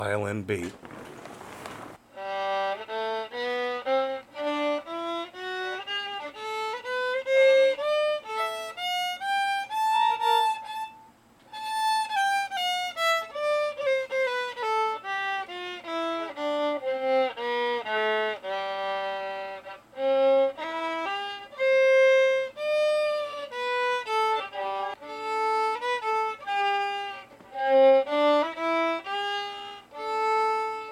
violin comparison.
I know the bowing is terrible and needs work.
I thought initially that Violin B was a better sounding one and you played it better but after about 6 times of hearing the same stanza of notes I have changed my thinking to the violin A because it has better resonance or at least the bow and your playing are more compatible currently.
I also "see" thru my ears that you are off slightly in your timing when doing a note change, as you are changing direction with the bow then.
violin-b.mp3